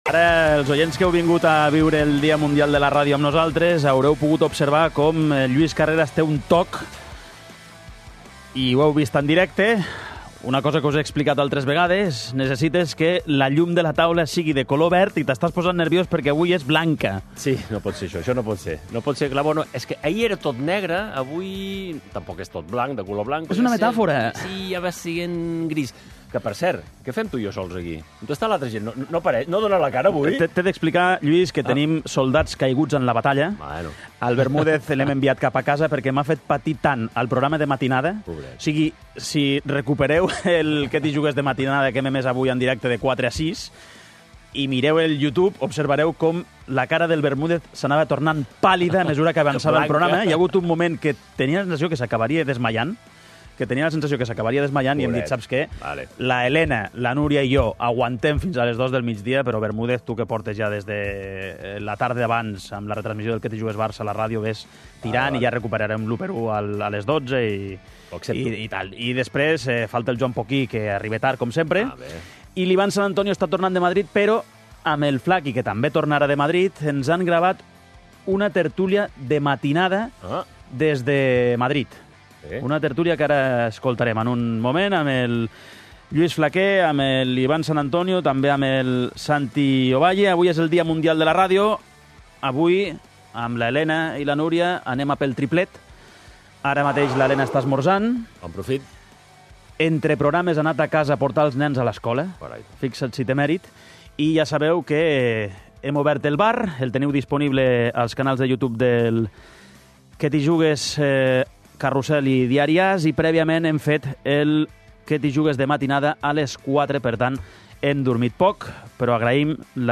Al 'Què t'hi Jugues!' fem tertúlia